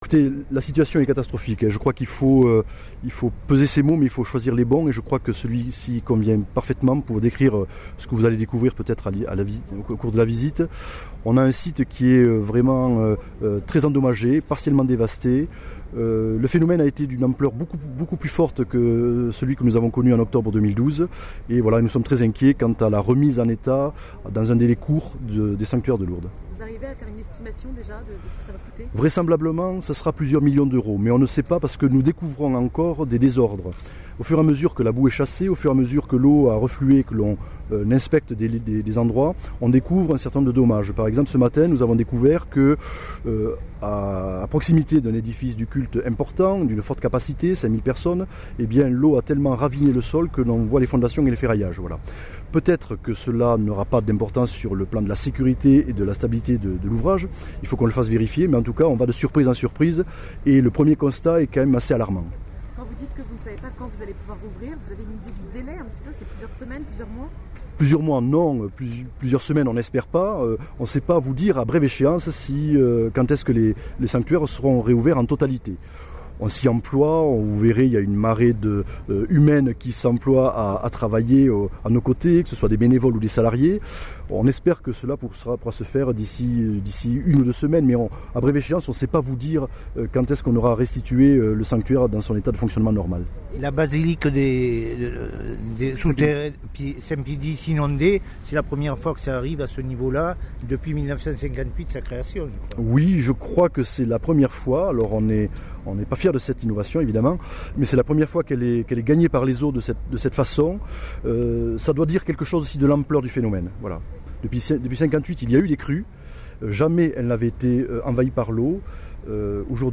Nous mettons en ligne l’intégralité du point-presse et un premier diaporama qui situe bien l’ampleur de la tâche qui attend les entreprises, les salariés des sanctuaires et les bénévoles pour permettre une reprise rapide de la vie de ce haut-lieu de la chrétienté.